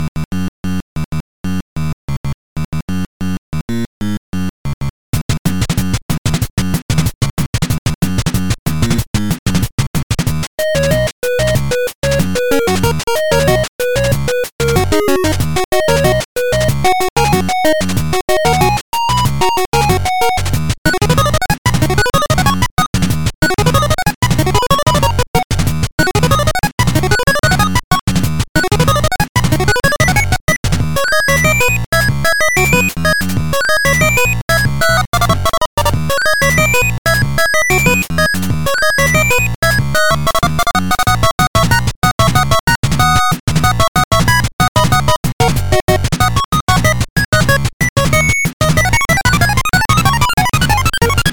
ファミコン風音楽素材です。